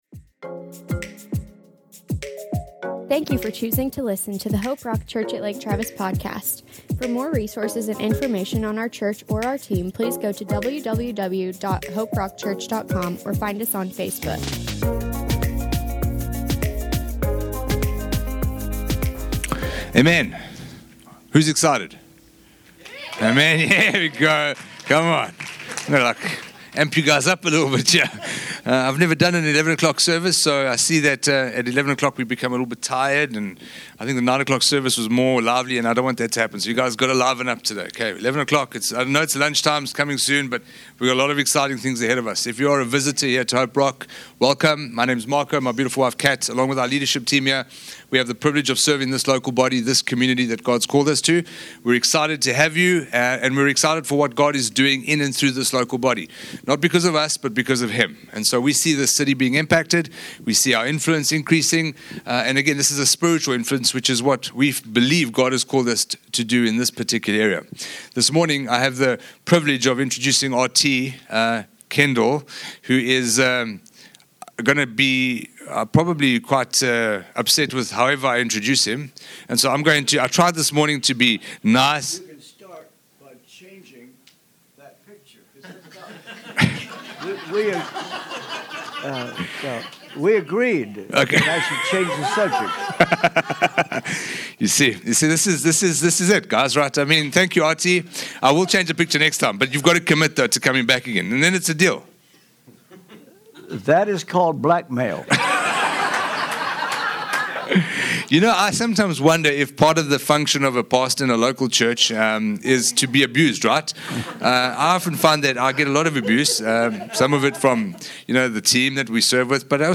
RT Kendall ministered at our 11 am service on Total Forgiveness from Genisis 45